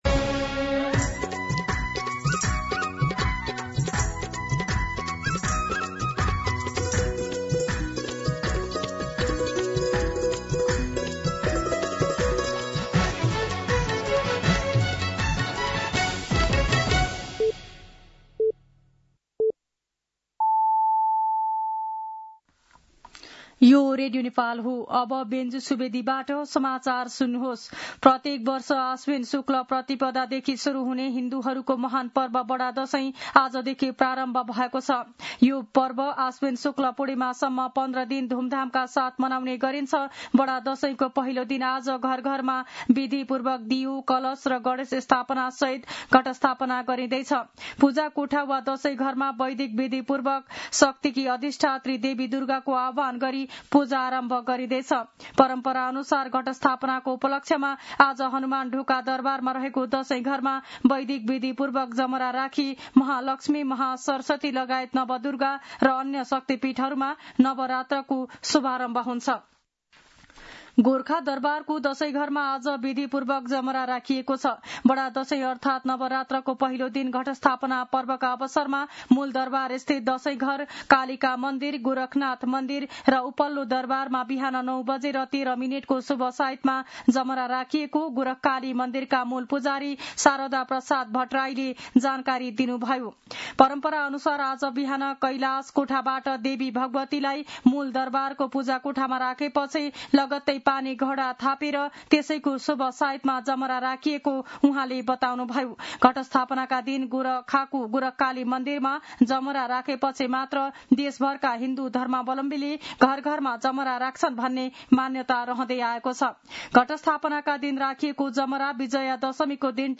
मध्यान्ह १२ बजेको नेपाली समाचार : ६ असोज , २०८२
12-pm-Nepali-News-3.mp3